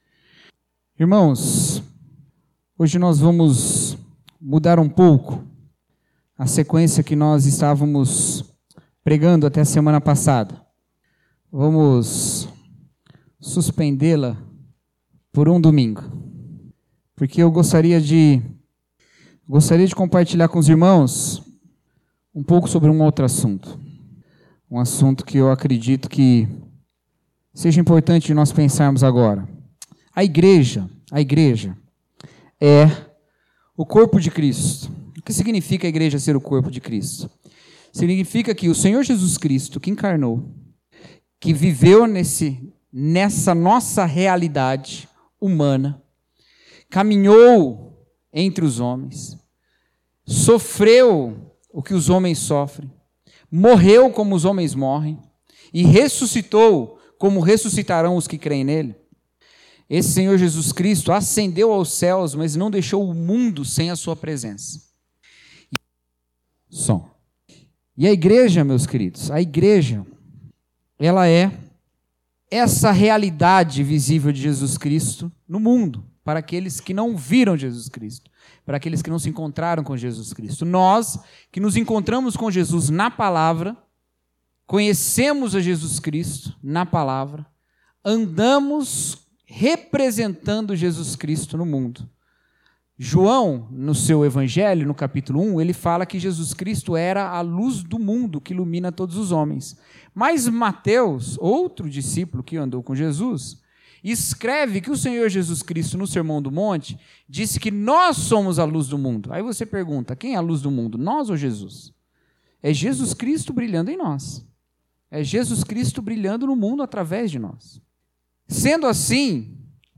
Mensagem: As Mortes do Homem